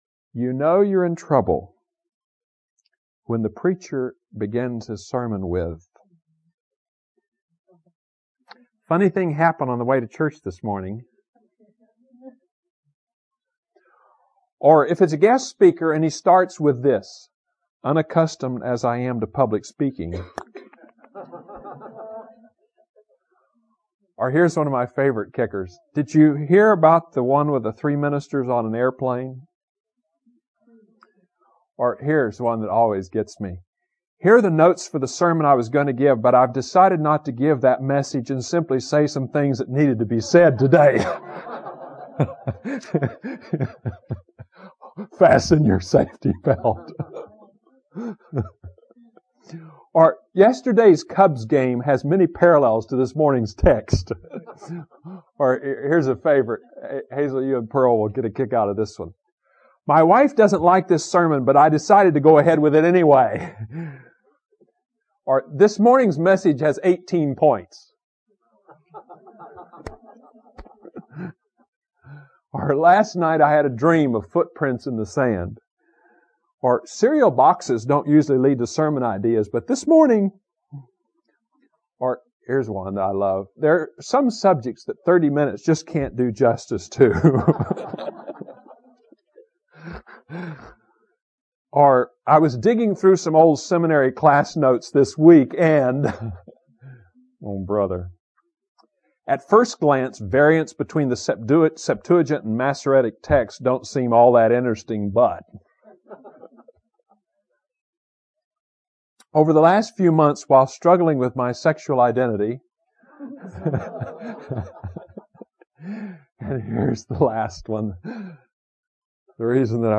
Online Sermon Worship 4